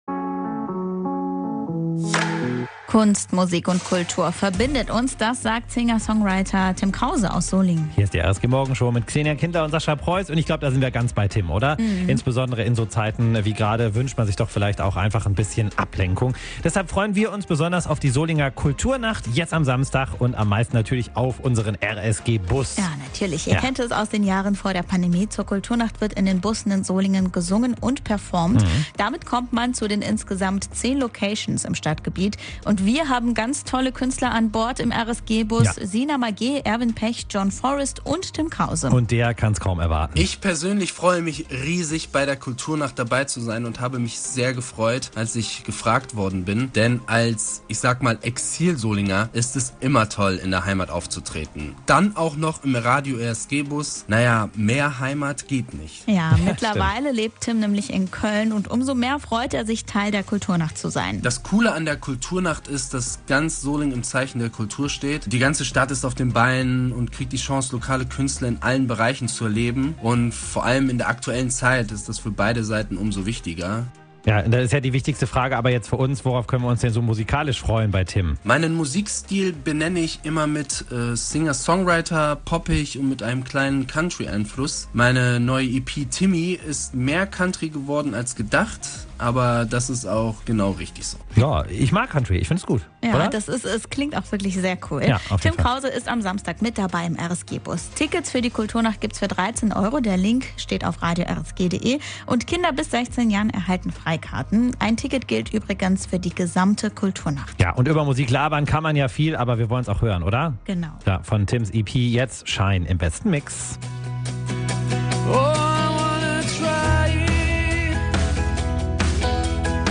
Kultur Nacht Solingen